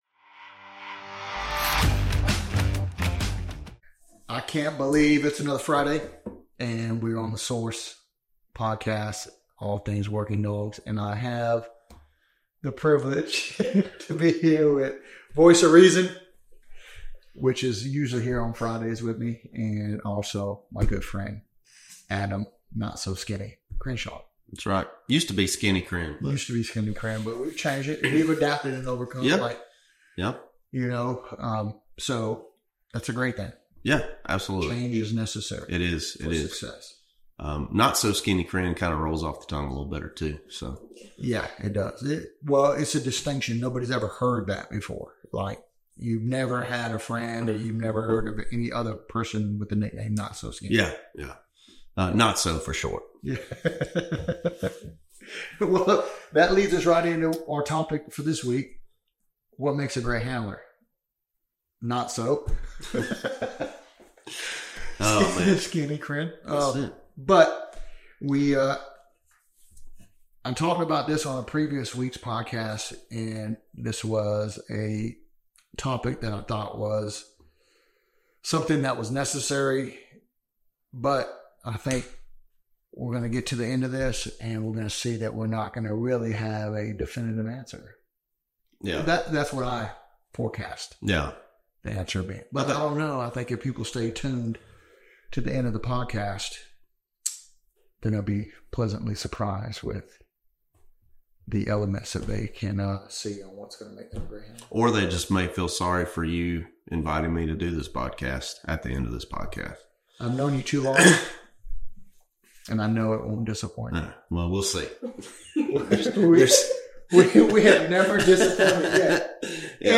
a seasoned K9 handler with a wealth of experience.